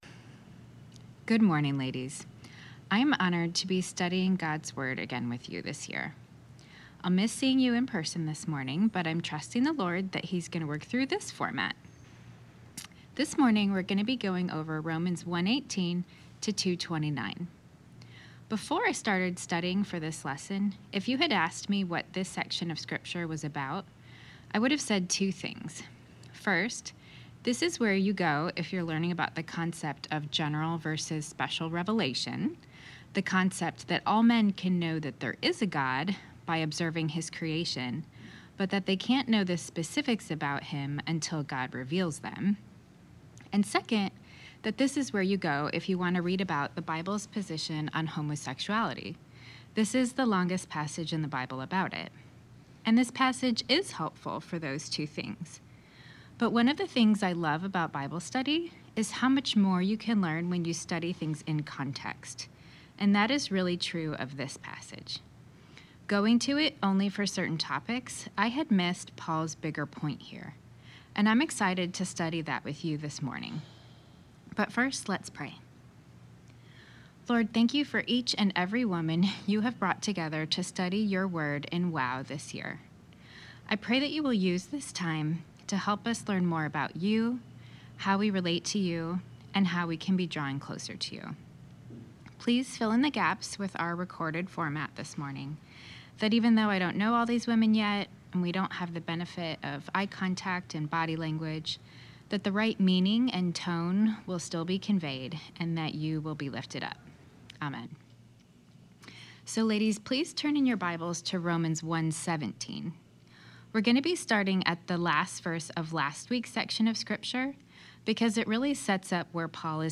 Women of the Word Wednesday Teaching Lesson 2: Romans 1:18-2:29 Sep 27 2023 | 00:25:15 1x 00:00 / 00:25:15 Subscribe Share RSS Feed Share Link Embed